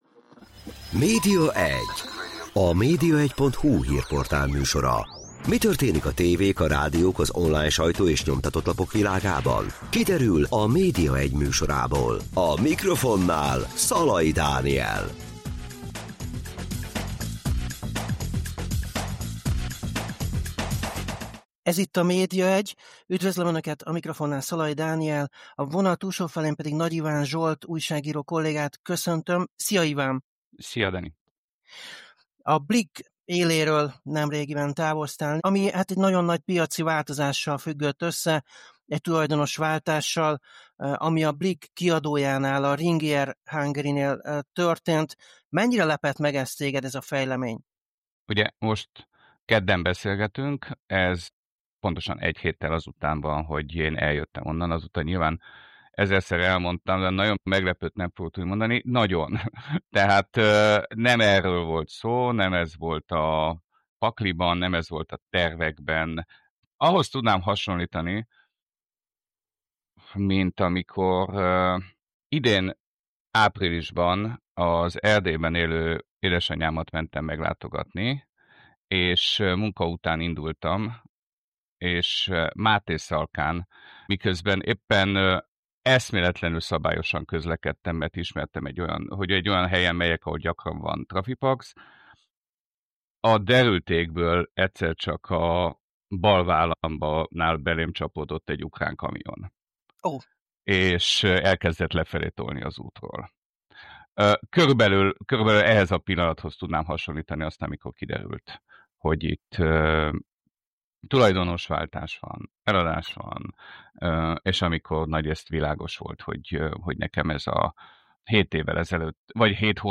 interjú